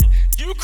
Kicks
JJKicks (13).wav